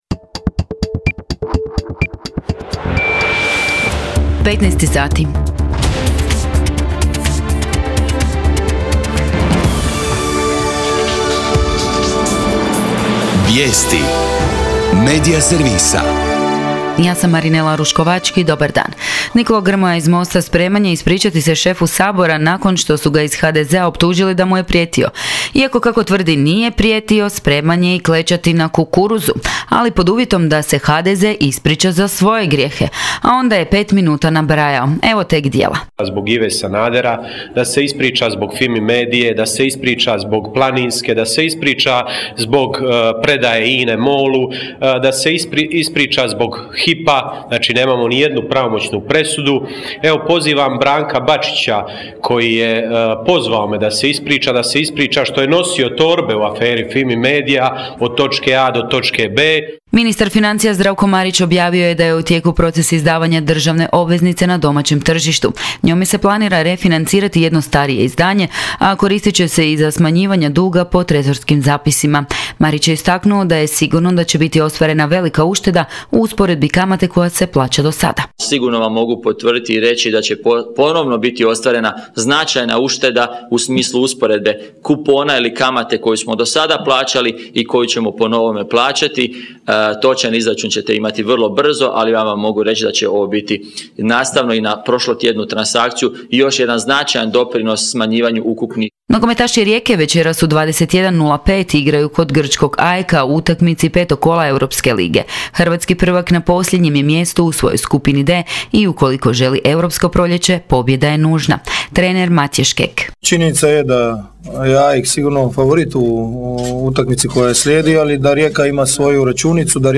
VIJESTI U 15